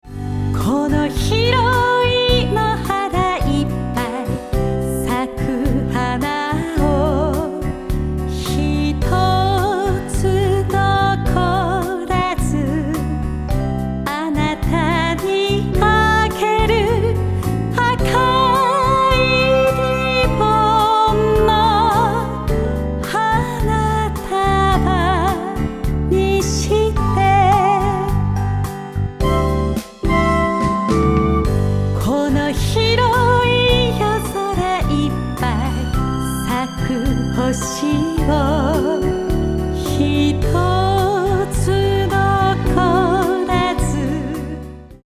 カバーアルバム
四季折々の美しい風景や豊かな心情が綴られた名曲を、心に寄り添うように優しく温かく歌い上げます。